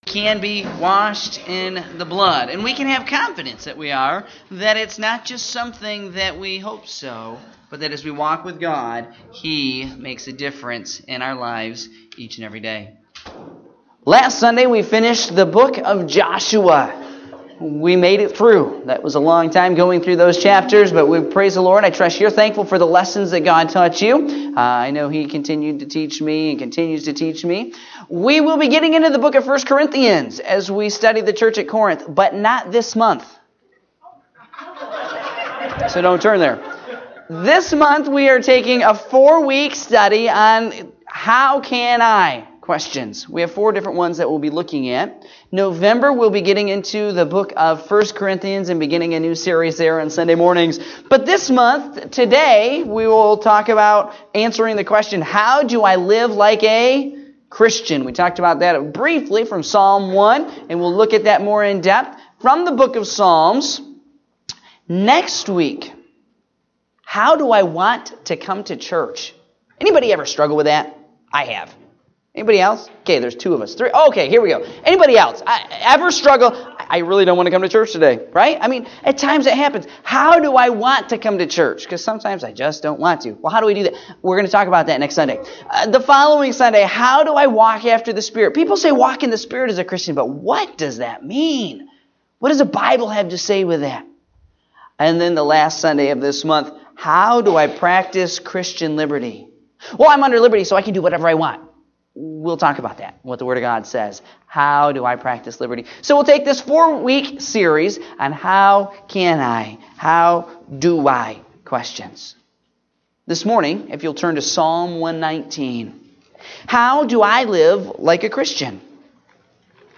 Morning Service (10/08/2017)